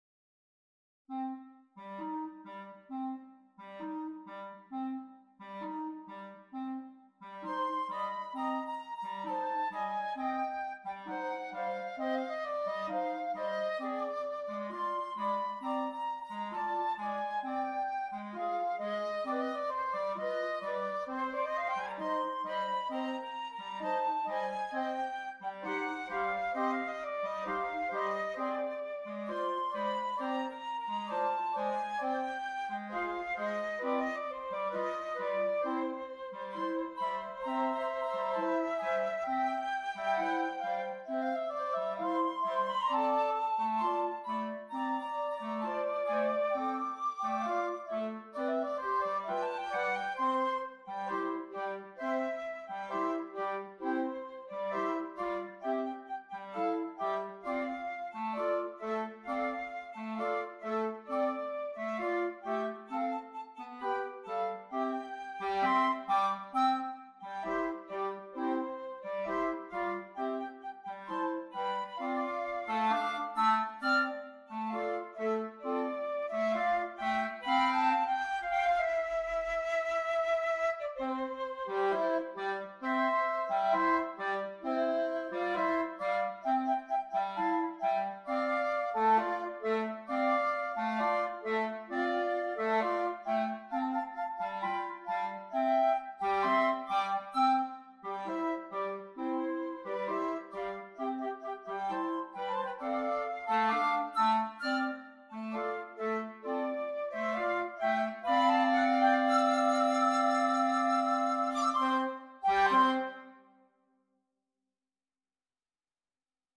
Voicing: 2 Flute and 2 Clarinet